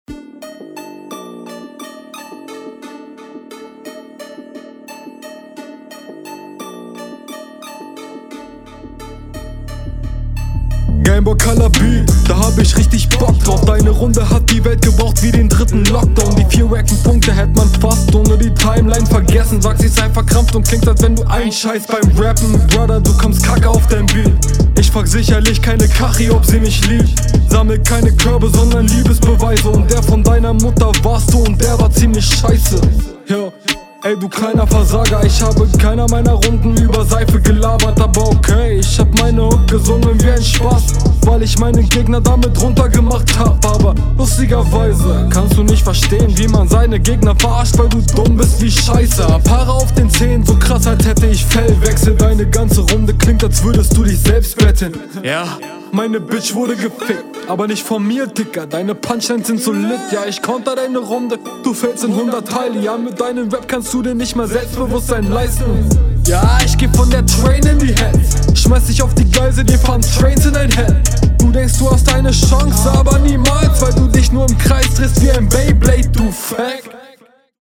Flowlich viel geordneter, Mische viel cleaner und ähnlich arrogante Stimmenlage, wobei ich hier sagen muss, …